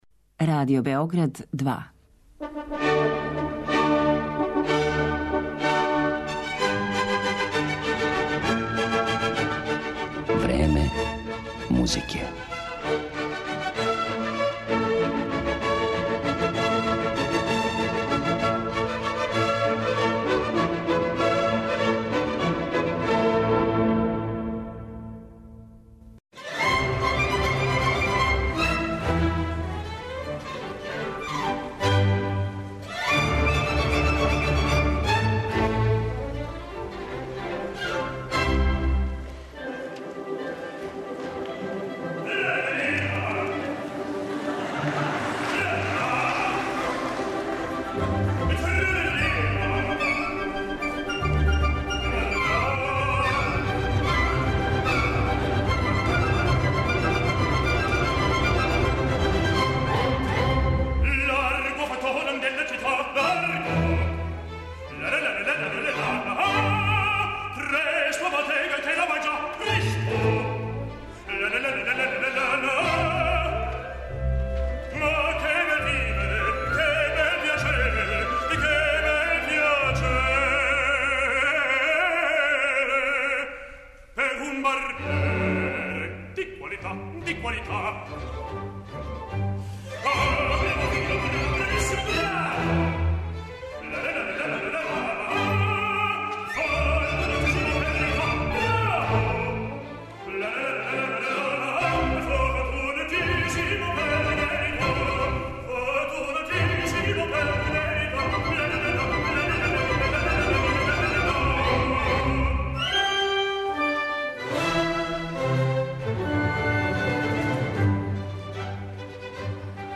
У емисији ћете, осим приче о његовој каријери и пeдагошком раду, моћи да чујете и одабране снимке из богатог Хемпсоновог репертоара, односно арије из опера "Севиљски берберин", "Хамлет", "Ловци на бисере", "Травијата", "Дон Карлос" и "Танхојзер".